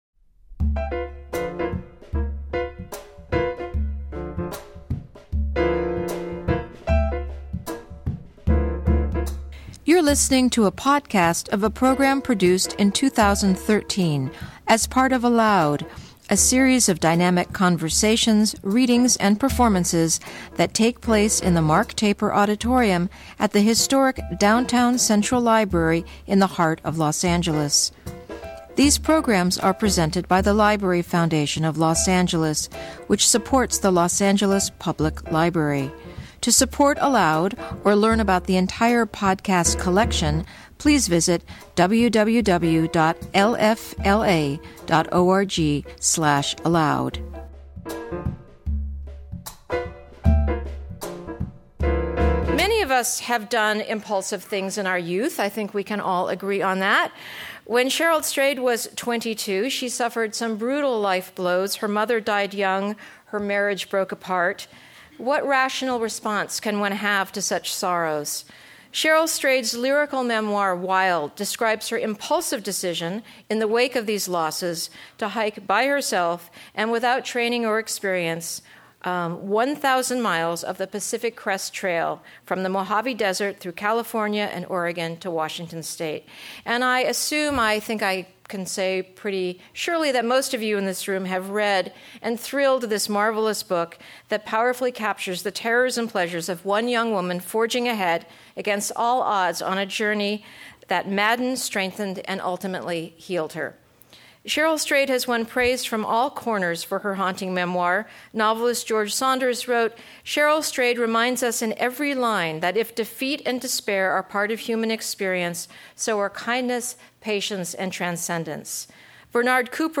Cheryl Strayed In conversation